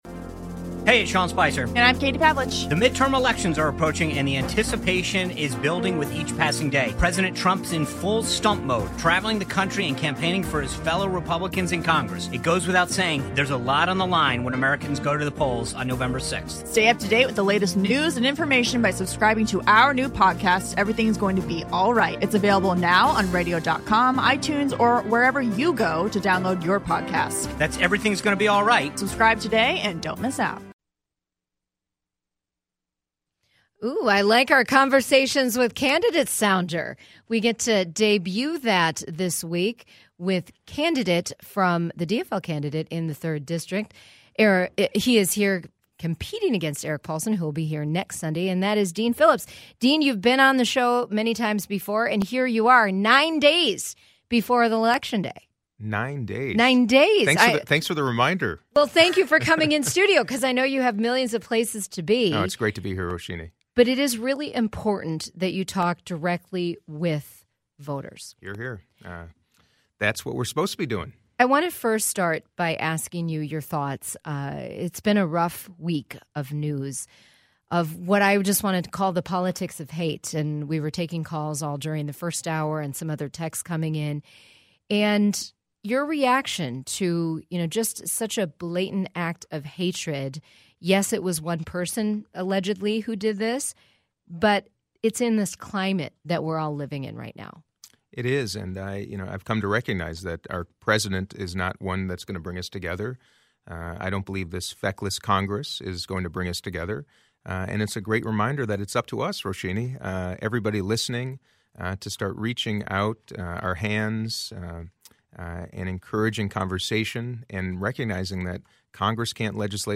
If you didn’t hear the initial airing of this interview, we’re providing it below: